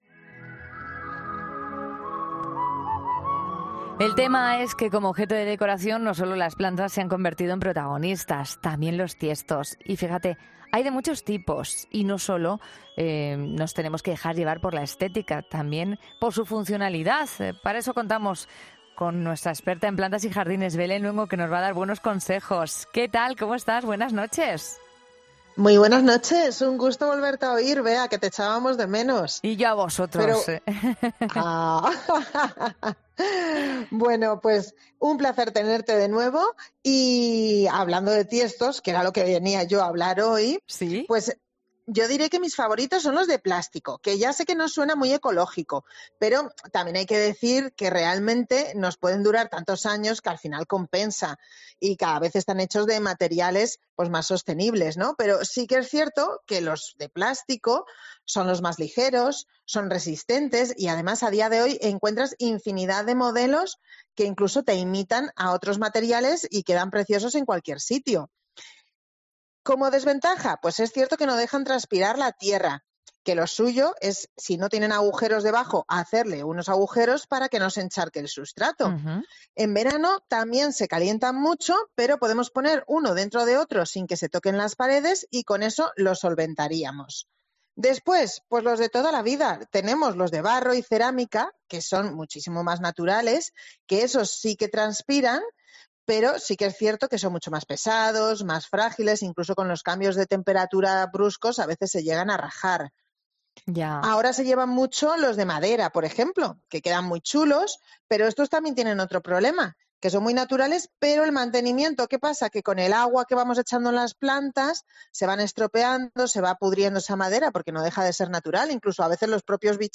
Una experta en jardinería resuelve esta duda